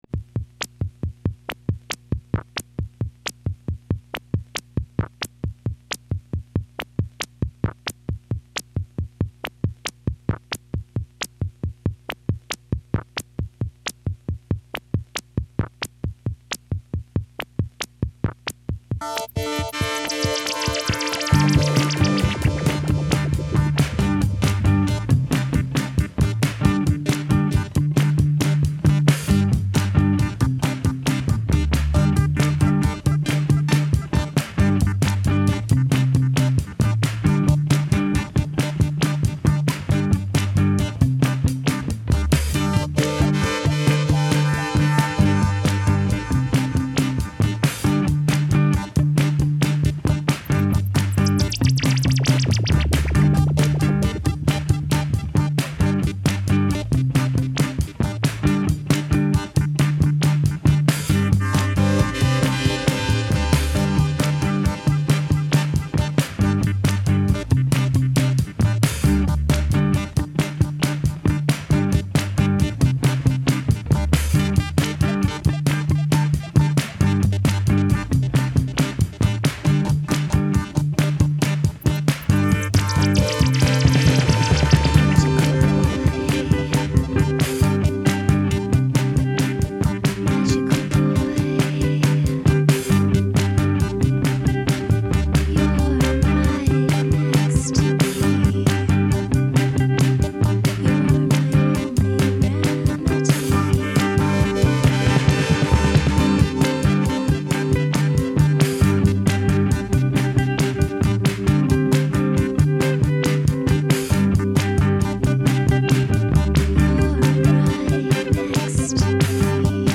the most rhythmic based music